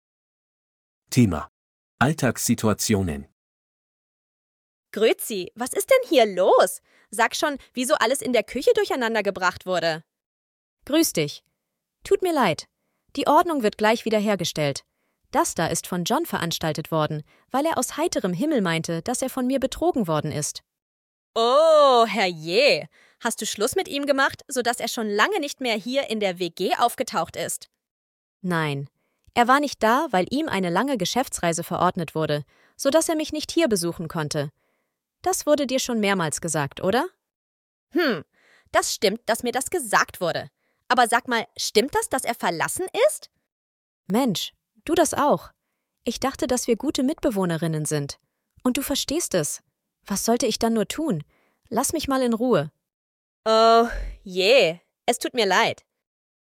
Audio text for the dialogs in task 4: